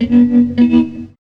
2604R GTRTON.wav